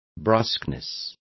Complete with pronunciation of the translation of brusqueness.